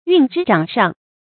运之掌上 yùn zhī zhǎng shàng
运之掌上发音